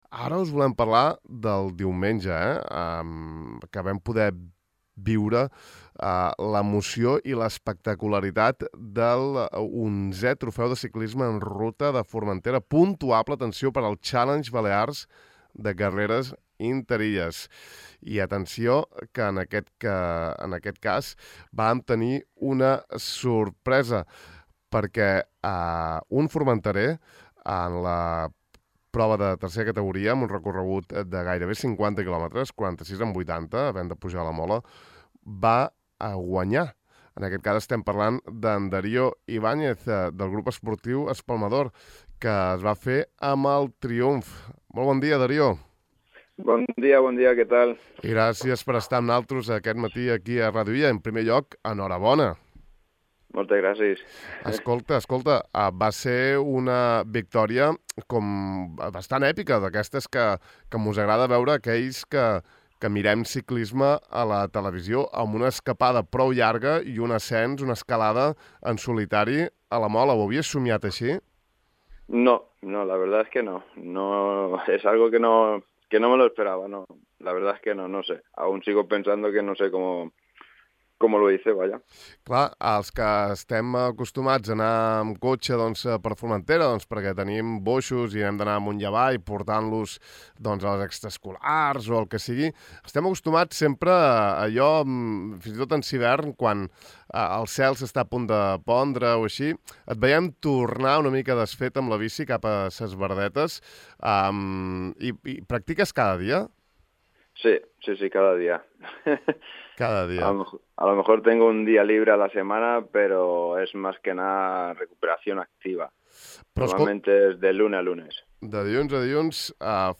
Avui hem conversat una bona estona amb ell de com es va desenvolupar la cursa i de la seva transició de les arts marcials a la bicicleta. https